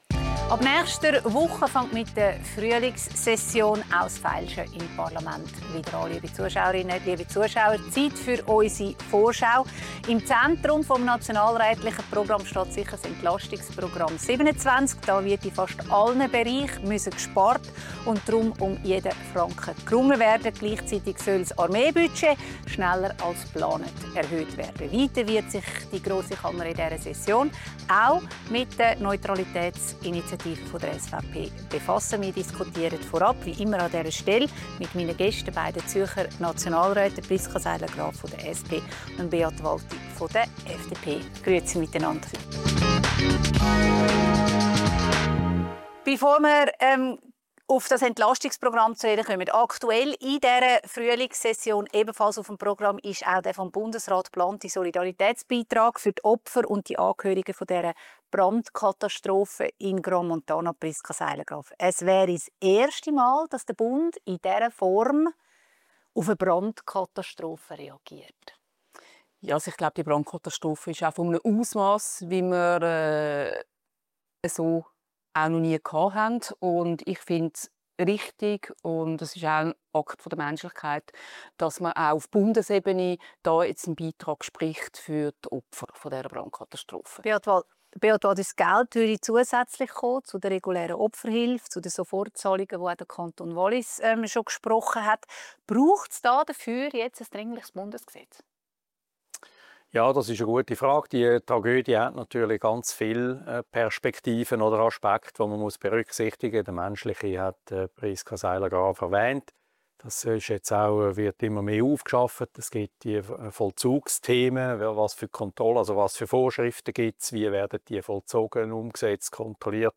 diskutiert mit den beiden Zürcher Nationalräten Priska Seiler Graf, SP und Beat Walti, FDP